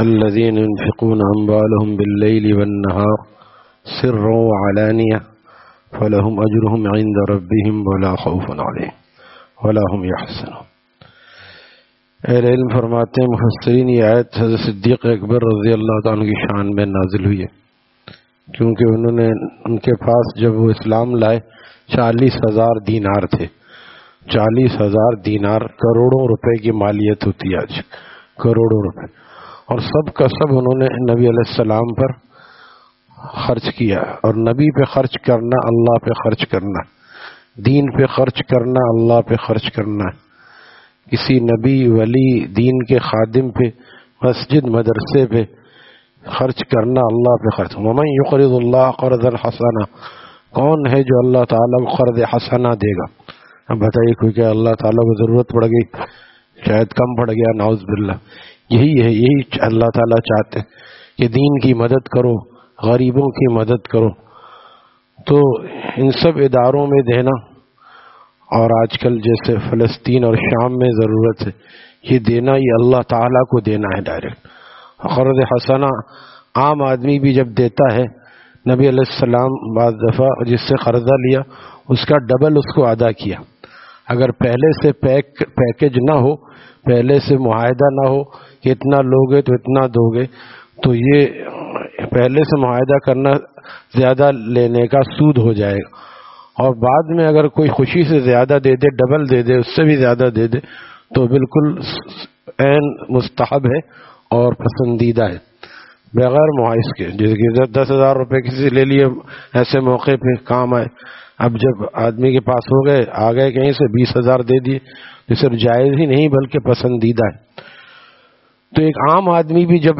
Taleem After Fajar at Jamia Masjid Gulzar e Muhammadi, Khanqah Gulzar e Akhter, Sec 4D, Surjani Town